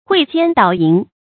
诲奸导淫 huì jiān dǎo yín
诲奸导淫发音